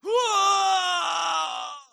Vampire_Death.wav